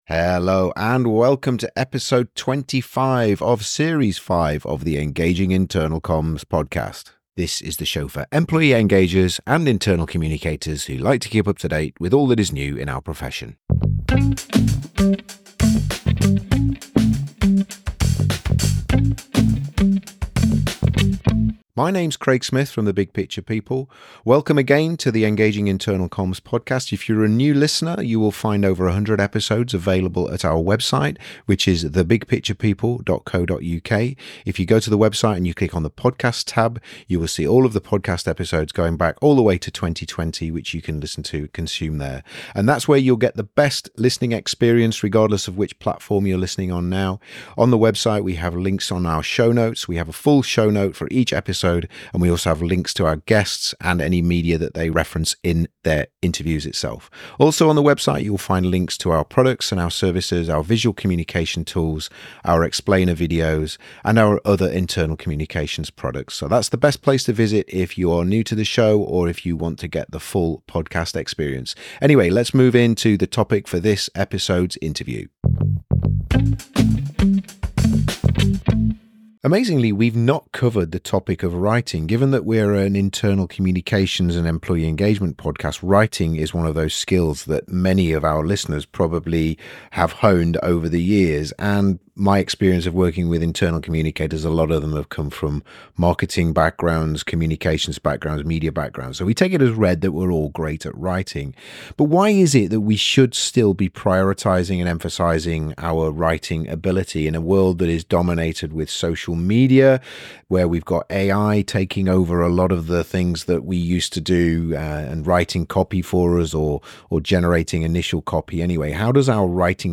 The interview identifies common writing challenges and offers strategies for improvement, including audience empathy and regular practice.